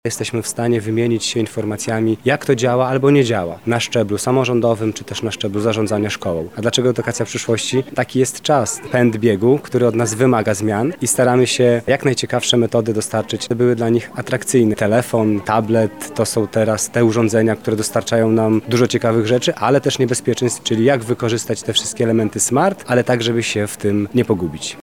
Podczas wczorajszej (15.03) konferencji lubelscy samorządowcy, nauczyciele i dyrektorzy rozmawiali o innowacji metod kształcenia, które pomogą uczniom wykorzystywać w przyszłości wiedzę zdobytą podczas lekcjach w szkole.
-mówi Maciej Nożyński, radny Rady Miasta Łuków, z zawodu nauczyciel.